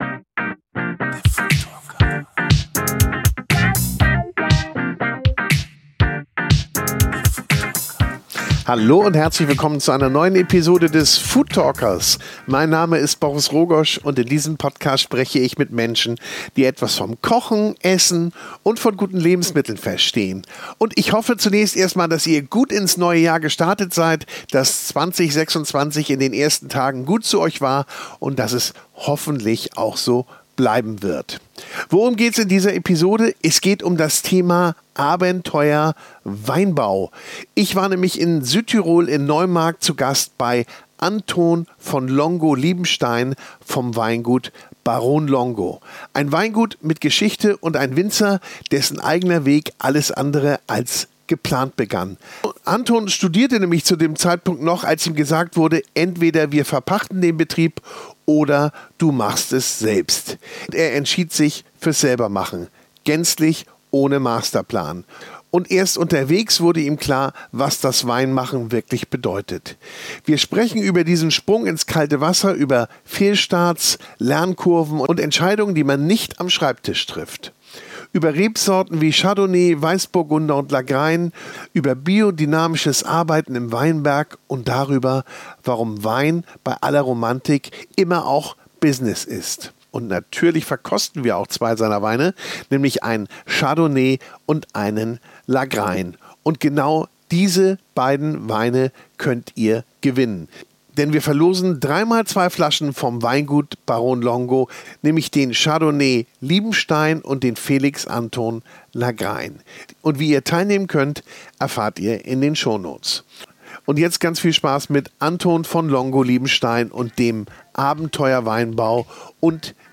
Natürlich wird auch verkostet.